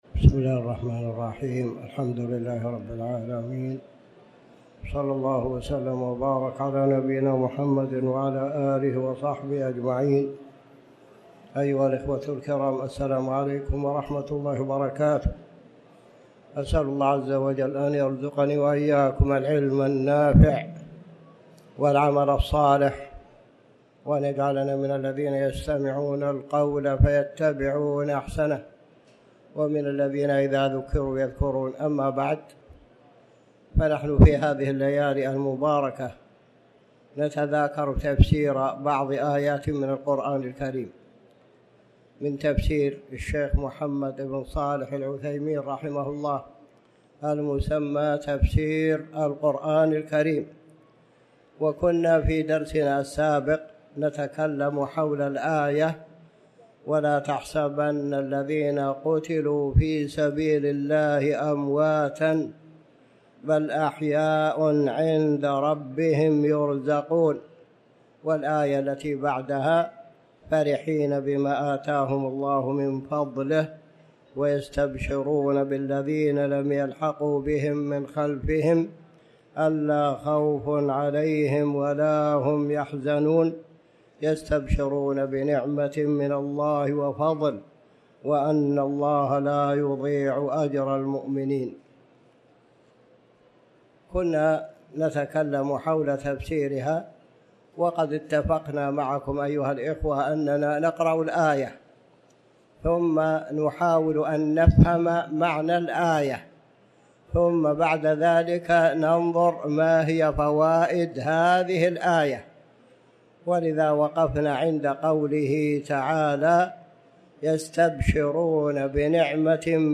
تاريخ النشر ٢٣ ربيع الأول ١٤٤٠ هـ المكان: المسجد الحرام الشيخ